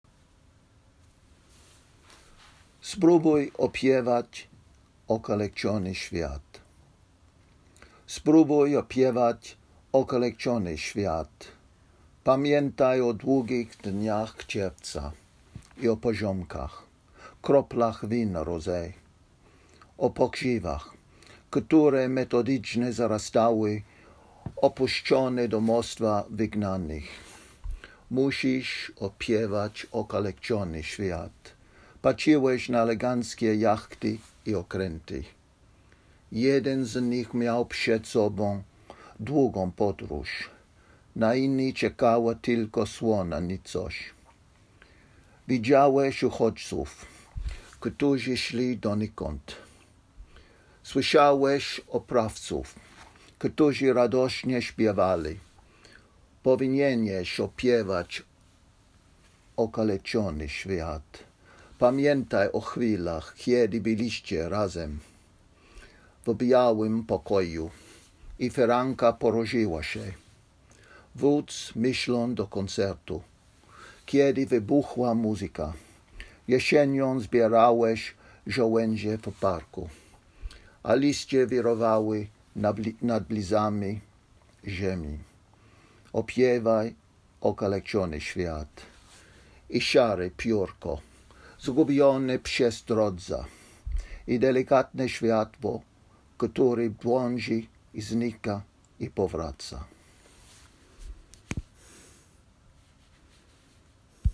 And if you’d like to hear what the original sounds like, here it is read in Polish: